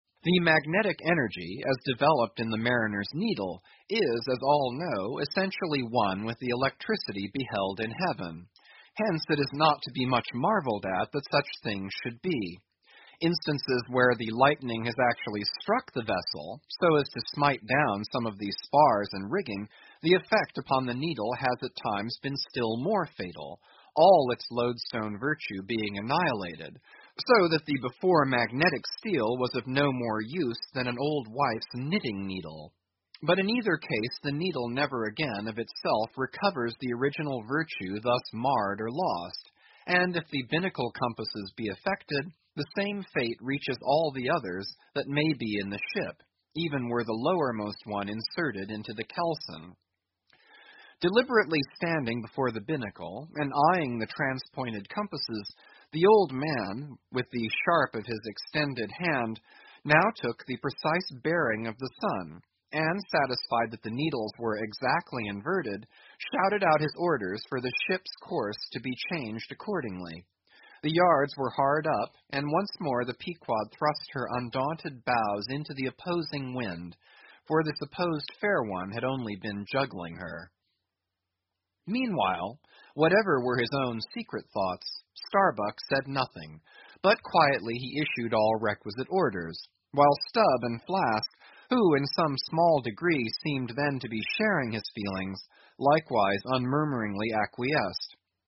英语听书《白鲸记》第960期 听力文件下载—在线英语听力室